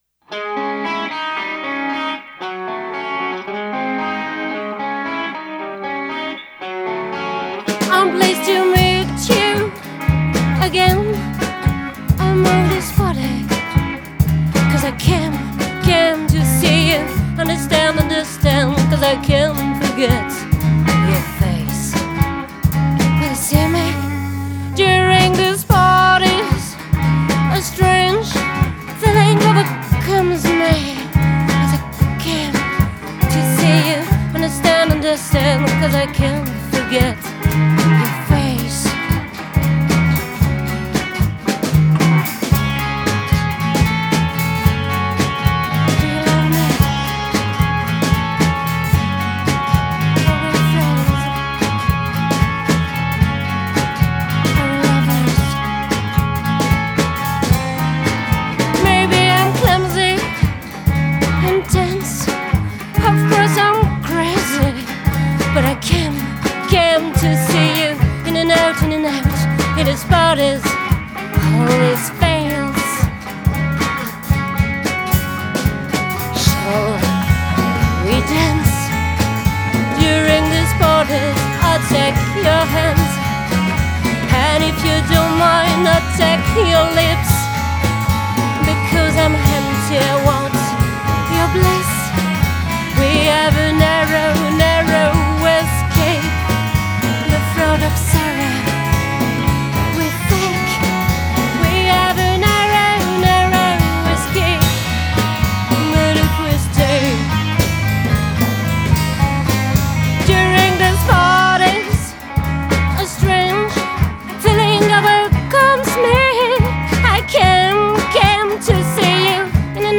guitares/chant
batterie
basse
Enregistré rue de la Fidélité, Paris 10,